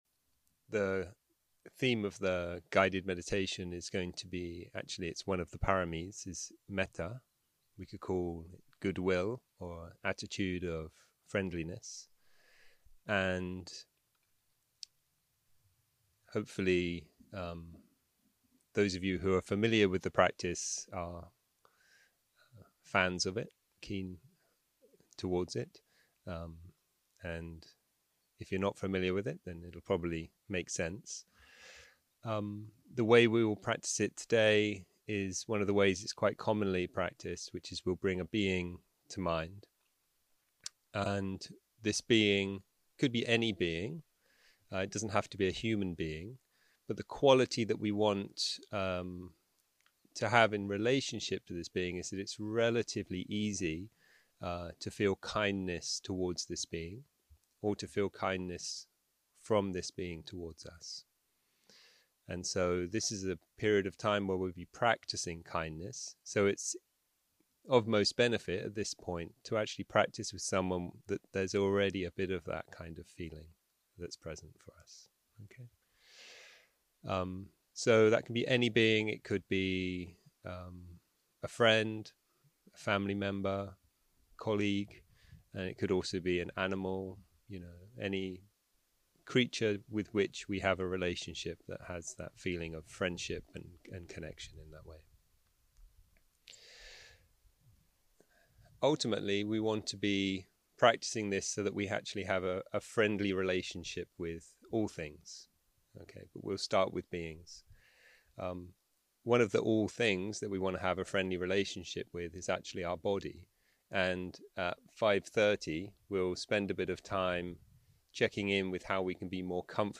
יום 2 - הקלטה 2 - צהרים - מדיטציה מונחית - מטא לדמות אהובה ולעצמי
יום 2 - הקלטה 2 - צהרים - מדיטציה מונחית - מטא לדמות אהובה ולעצמי 0:00 0:00 סוג ההקלטה: Dharma type: Guided meditation שפת ההקלטה: Dharma talk language: English